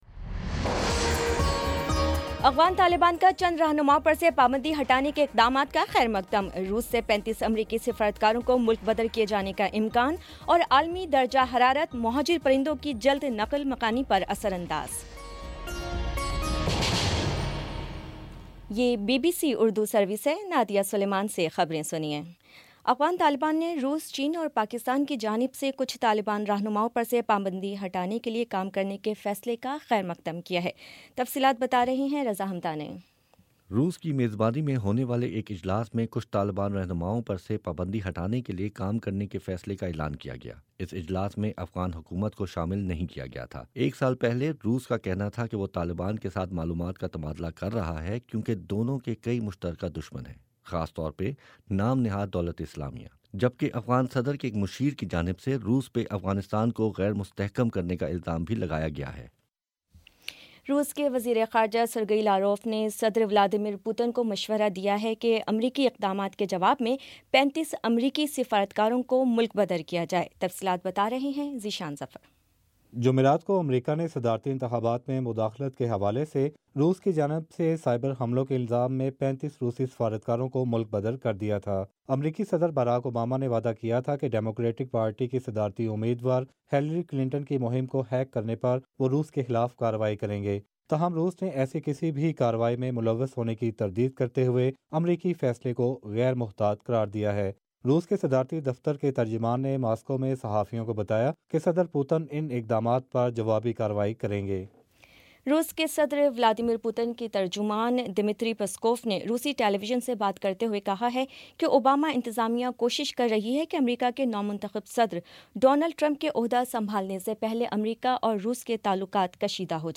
دسمبر 30 : شام پانچ بجے کا نیوز بُلیٹن